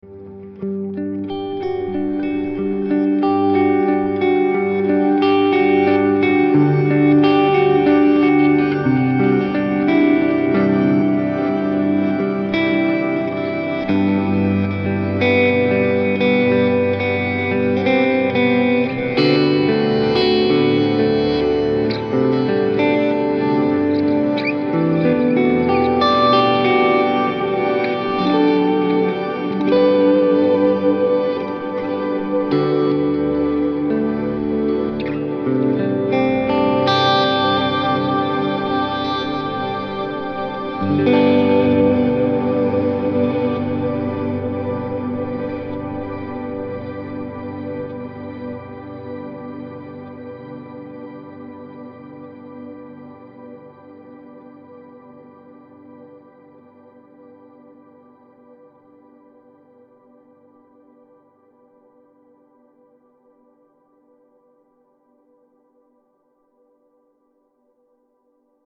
Create great sounding ambient music with just your guitar and Headrush with this pack from NVA.
A. HOLD: Freeze the chord or note being played
RAW AUDIO CLIPS ONLY, NO POST-PROCESSING EFFECTS